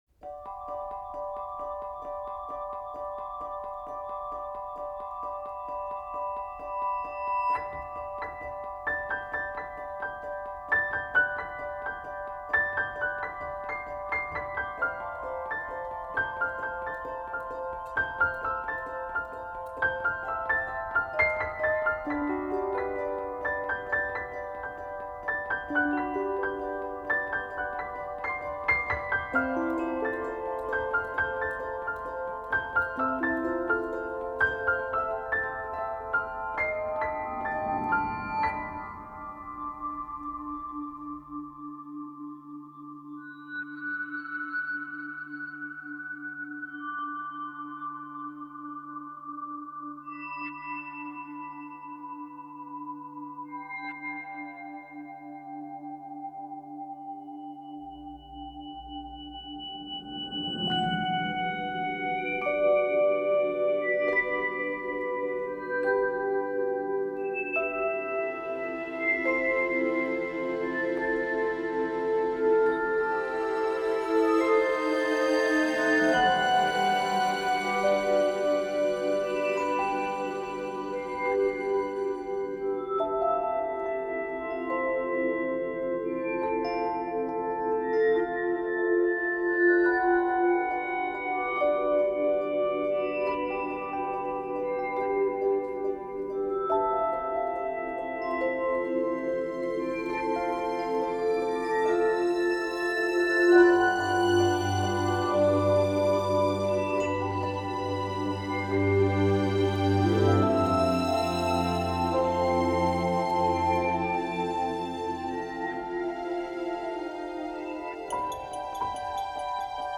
Soundtrack Instrumental Music